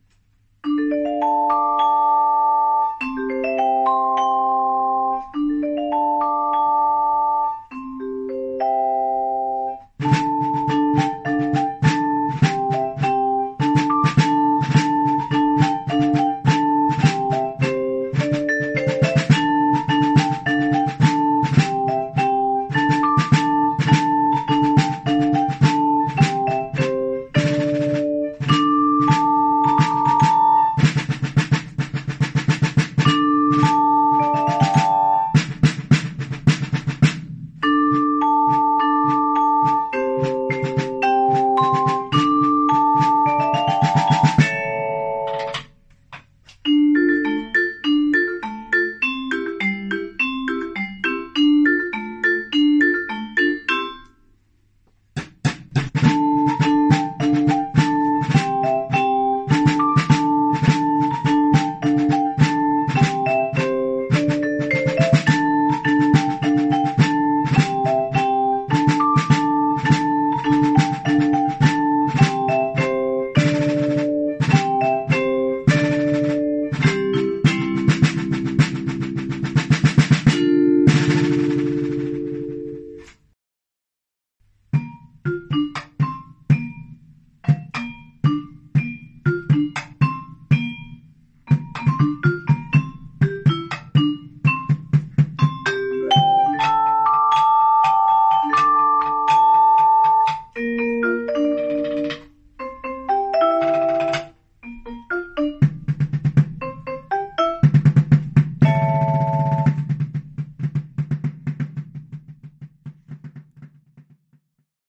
Oeuvre pour tambour et
vibraphone (ou piano).